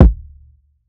Horrid Kick.wav